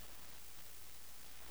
下圭柔山 e7-kue1-jiu5-san1 圭柔是平埔語